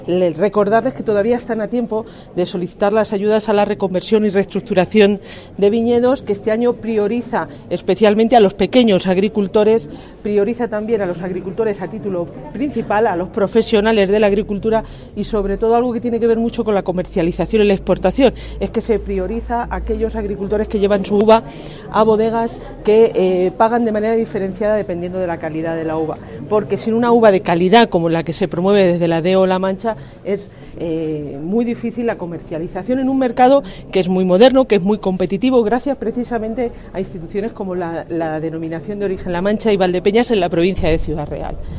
Así lo ha trasladado la representante del Ejecutivo autonómico  en el acto de entrega de premios del XXXI concurso a la calidad de vinos embotellados y varietales con DO La Mancha, que cuenta en la actualidad con 158.000 hectáreas de viñedo en 182 municipios de la región, resaltando “el peso económico” que juegan las cooperativas y bodegas en los municipios en  esta comarca en el embotellado y etiquetado bajo esta figura de calidad.